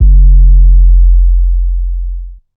SUB BOOM78.wav